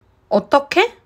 「どうやって？」と尋ねるときには語尾が上がります。